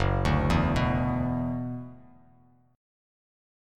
Gb7sus4#5 chord